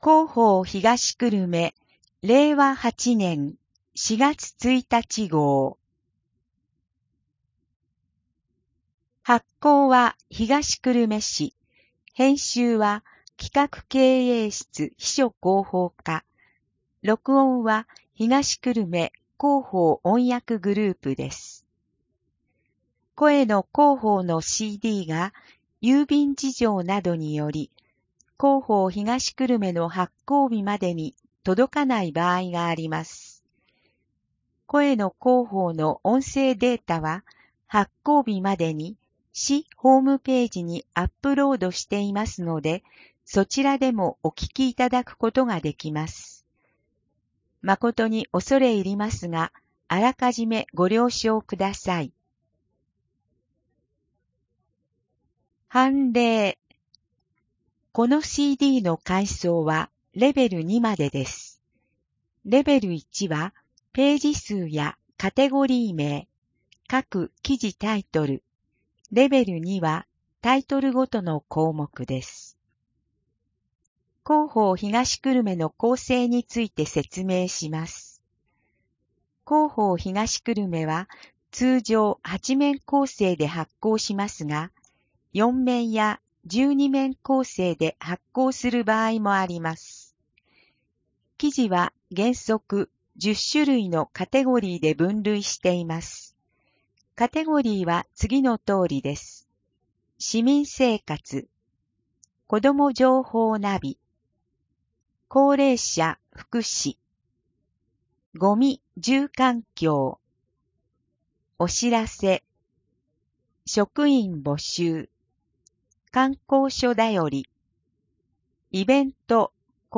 声の広報（令和8年4月1日号）